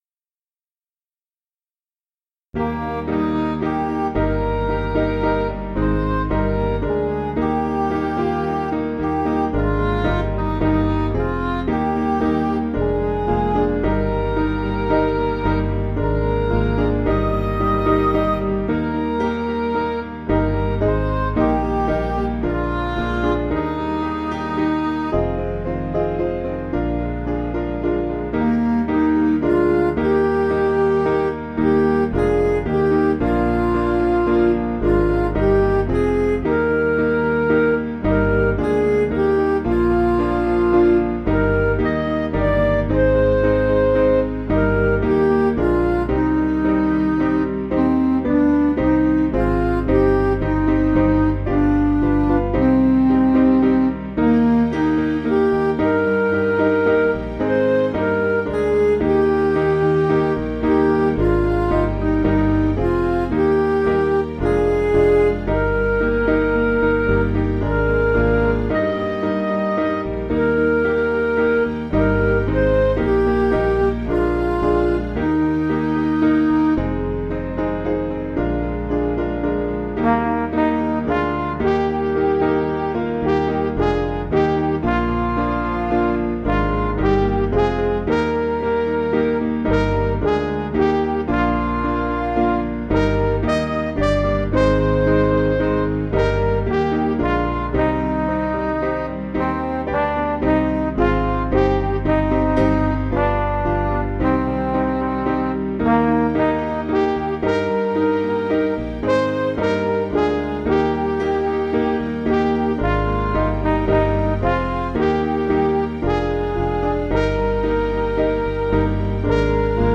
Piano & Instrumental
(CM)   5/Eb